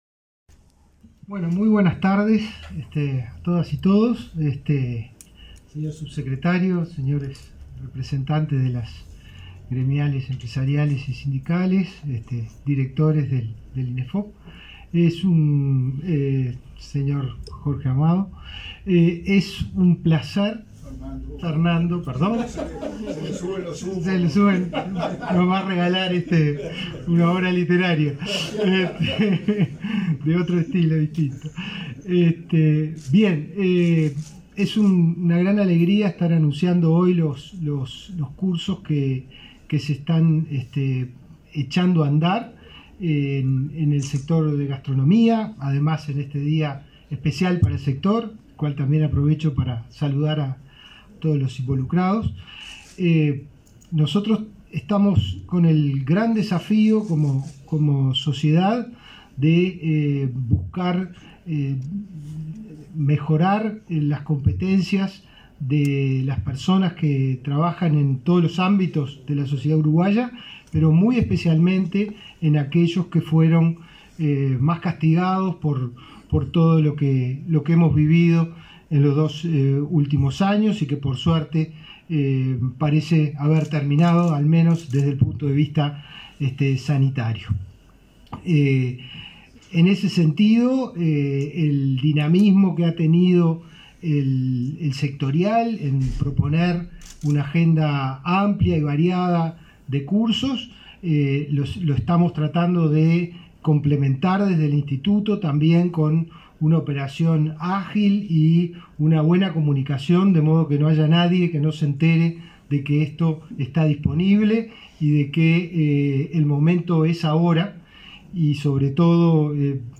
Conferencia de prensa por el lanzamiento de cursos de gastronomía del Inefop
Conferencia de prensa por el lanzamiento de cursos de gastronomía del Inefop 17/08/2022 Compartir Facebook X Copiar enlace WhatsApp LinkedIn El Instituto de Empleo y Formación Profesional (Inefop) lanzó, este 17 de agosto, los cursos destinados al sector gastronómico. Participaron en el evento el director del Inefop, Pablo Darscht, y el ministro interino de Turismo, Remo Monzeglio.